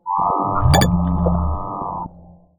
UI_SFX_Pack_61_11.wav